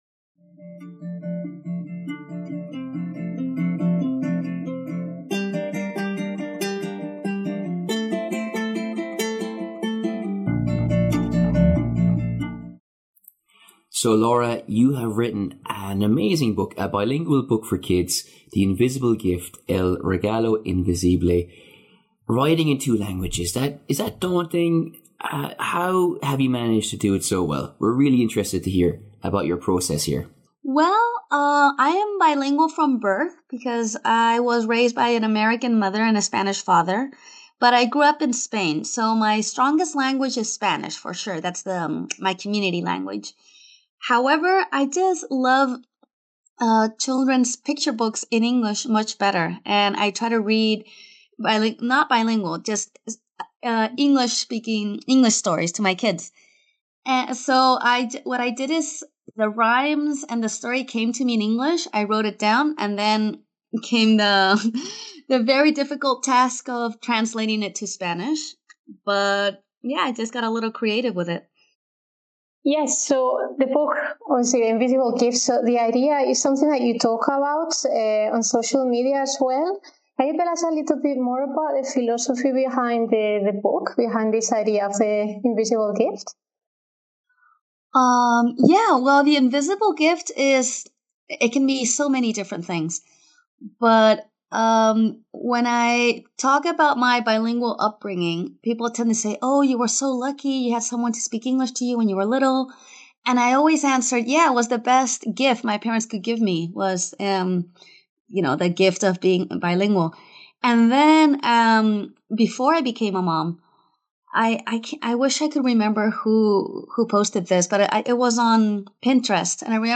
The People Will Talk Podcast / Interviewing